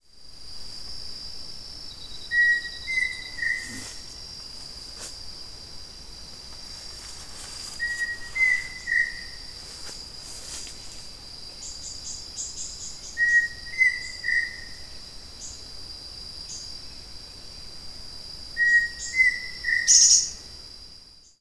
Yasiyateré Grande (Dromococcyx phasianellus)
Nombre en inglés: Pheasant Cuckoo
Localidad o área protegida: Reserva San Rafael
Localización detallada: Senderos de la Estación Kanguery
Condición: Silvestre
Certeza: Observada, Vocalización Grabada
Dromococcyx-phasianellus.mp3